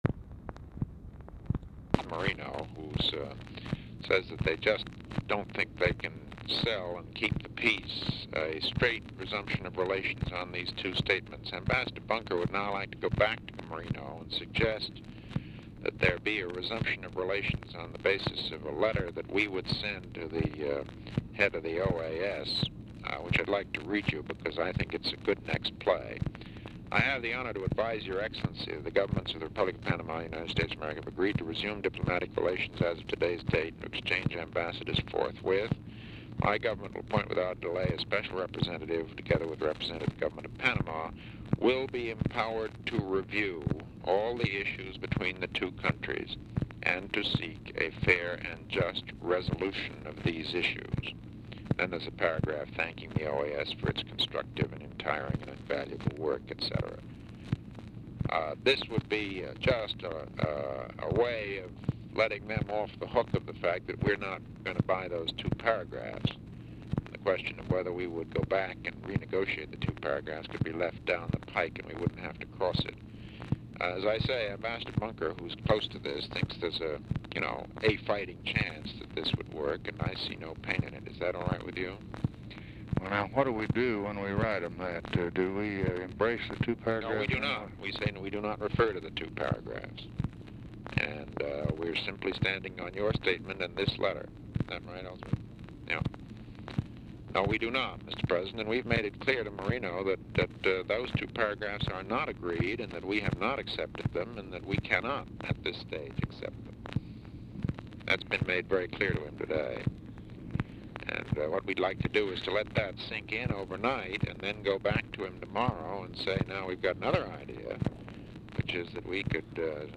RECORDING STARTS AFTER CONVERSATION HAS BEGUN; BUNDY SPEAKS TO ELLSWORTH BUNKER WITH WHOM HE IS MEETING AT TIME OF CALL; BUNKER'S RESPONSES ARE INAUDIBLE
Format Dictation belt
Specific Item Type Telephone conversation Subject Diplomacy Latin America Treaties